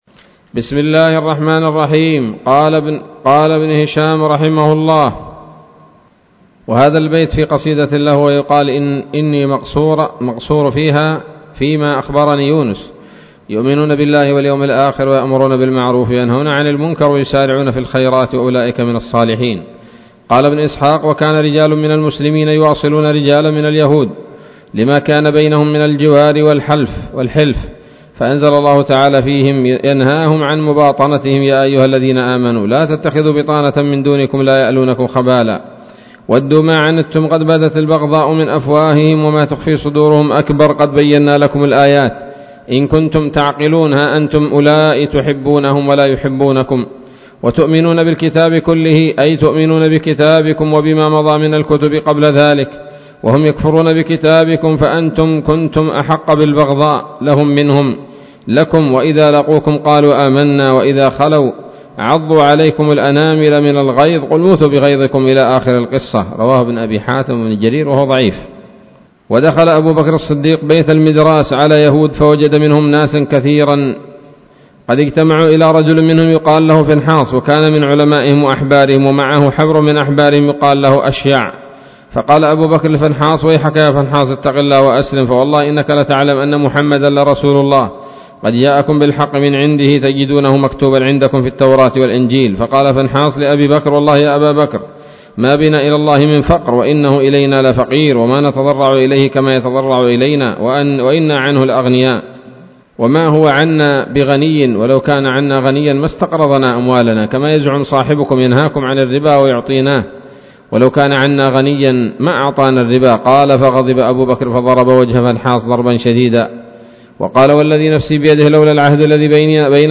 الدرس الخامس والتسعون من التعليق على كتاب السيرة النبوية لابن هشام